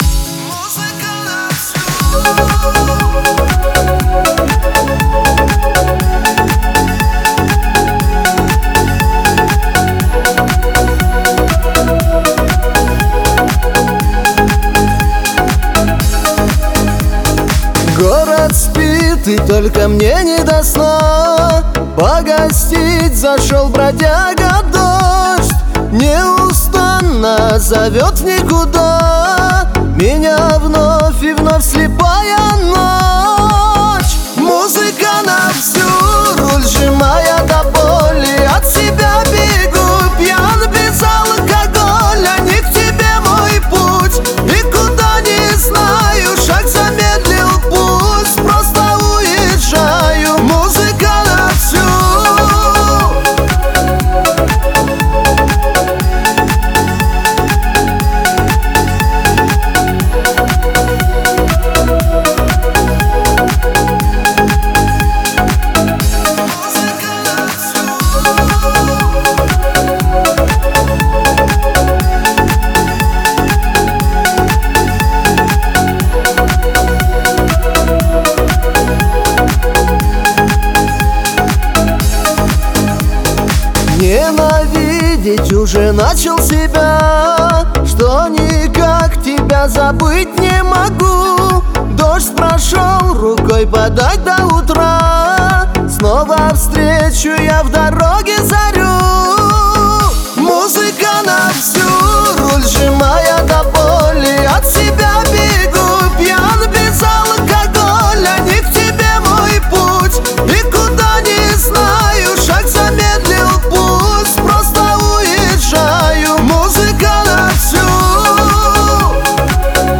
Шансон песни